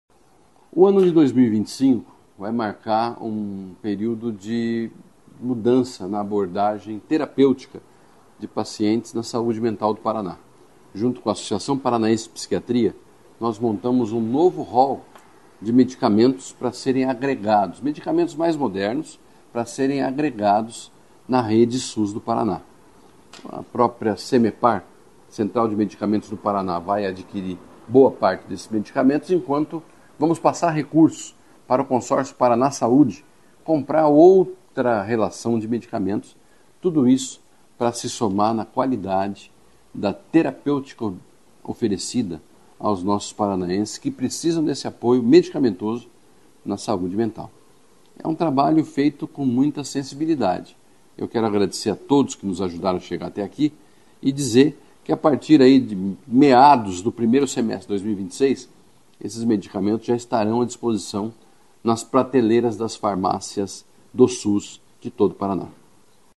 Sonora do secretário Estadual da Saúde, Beto Preto, sobre a ampliação da oferta de medicamentos de saúde mental pelo SUS no Paraná